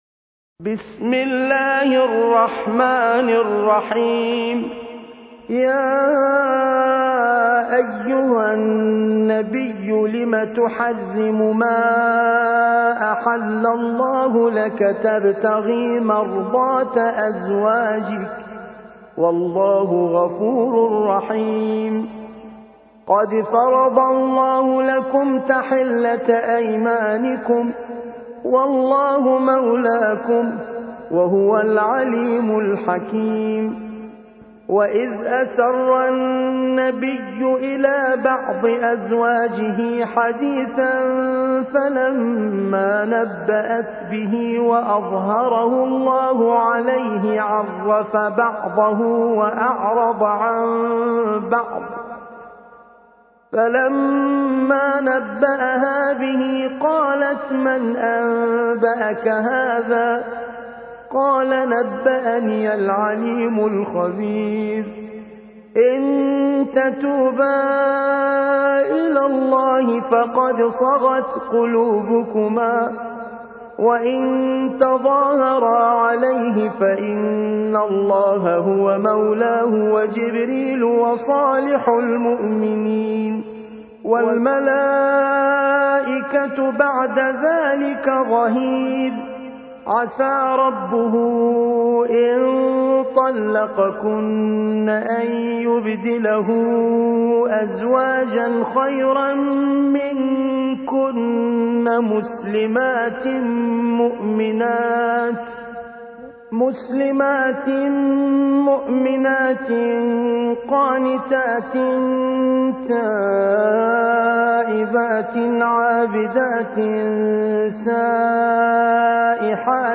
66. سورة التحريم / القارئ